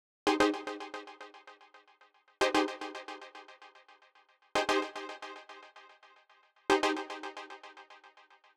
06 Chord Synth PT3.wav